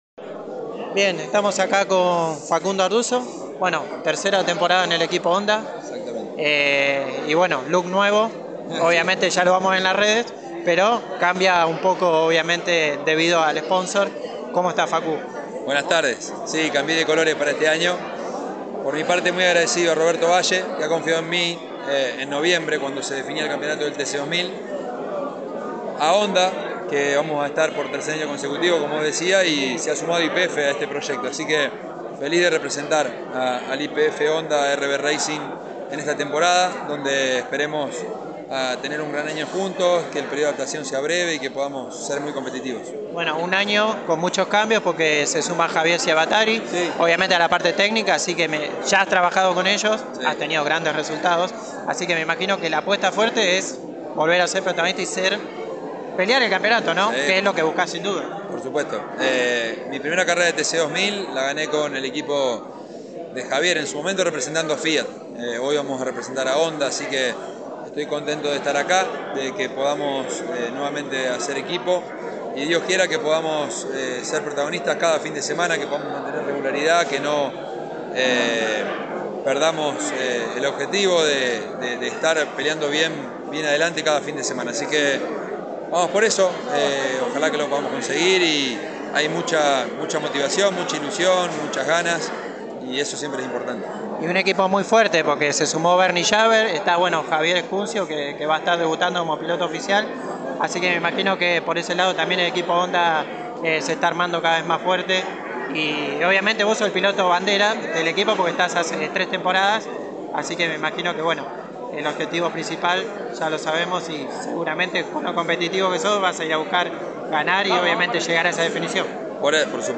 El piloto de Las Parejas selló su continuidad a fines del año pasado con el equipo Honda y afrontará su tercera temporada con la marca japonesa. Ardusso diálogo con Pole Position y manifestó su alegría del equipo que se formó para esta temporada, donde buscarán recuperar el protagonismo.